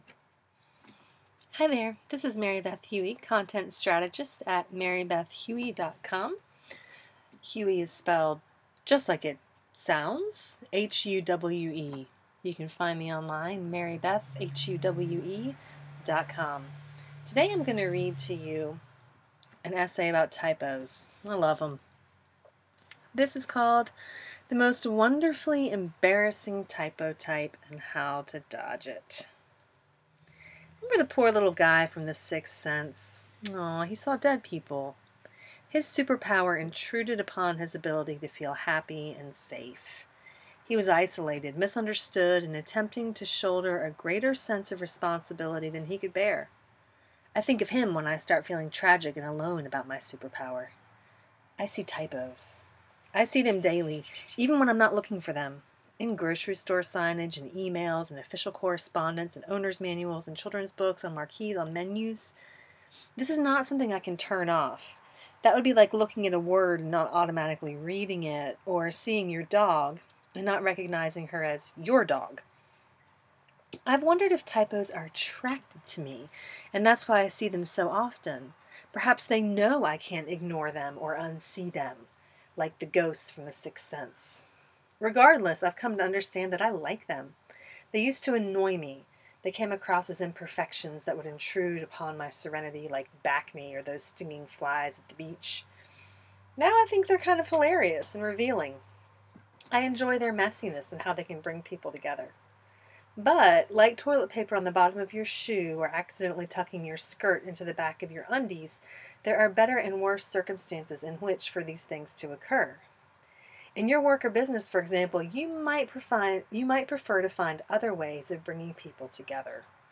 Click the “play” arrow if you’d like me to read this essay to you.